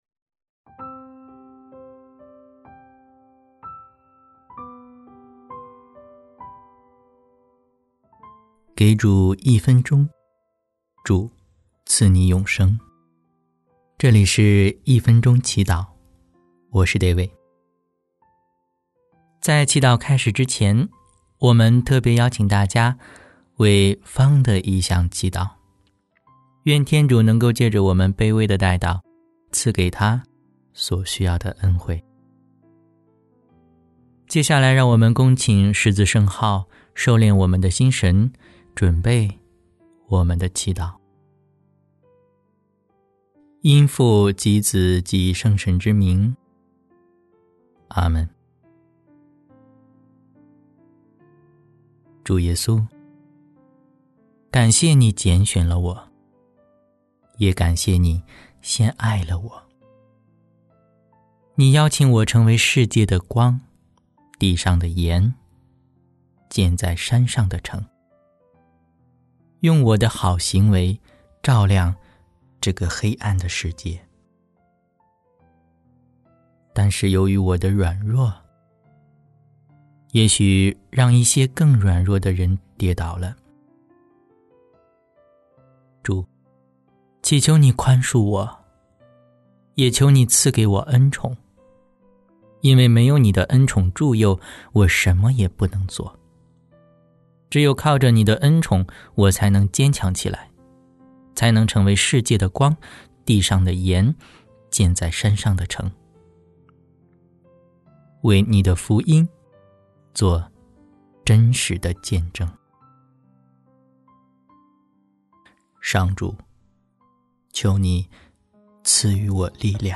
【一分钟祈祷】|主，祈求祢宽恕我，也求祢赐给我恩宠（2月24日）